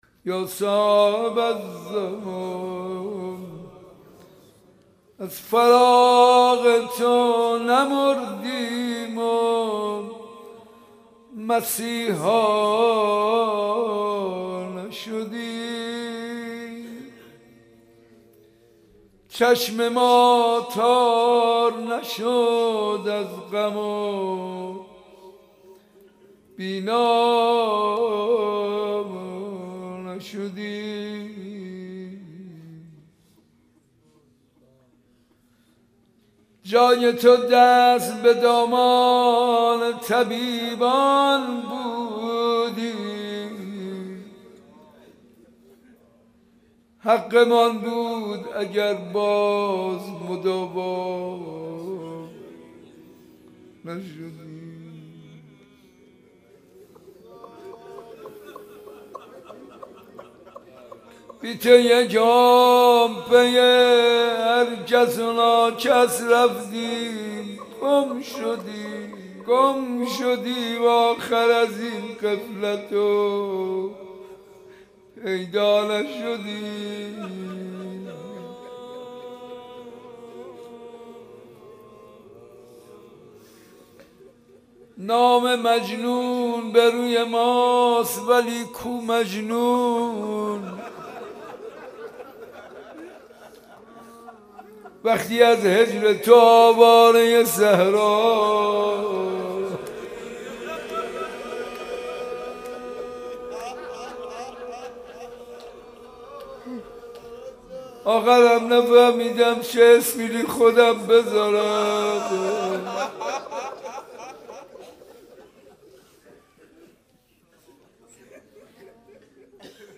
مراسم زیارت عاشورا حسینیه صنف لباسفروشها 18 آذر
با مداحی حاج منصور ارضی برگزار گردید